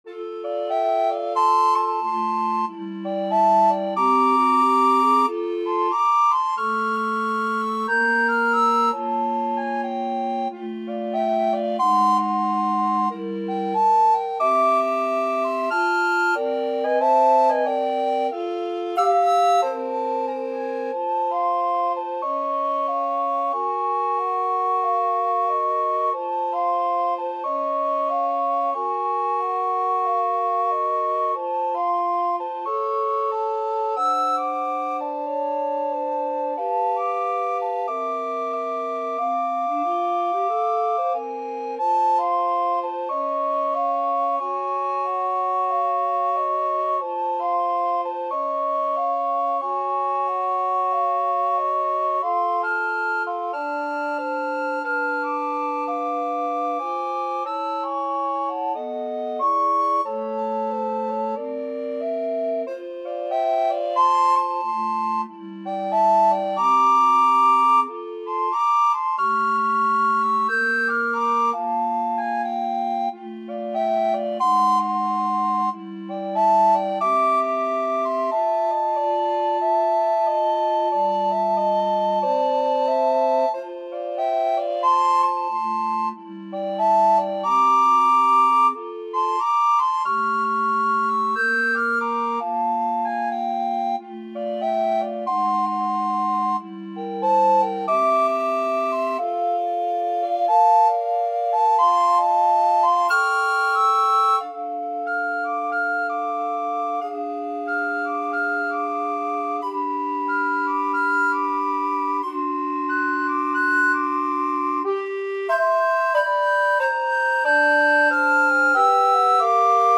Soprano RecorderAlto RecorderTenor RecorderBass Recorder
2/2 (View more 2/2 Music)
Moderato = c. 46
Jazz (View more Jazz Recorder Quartet Music)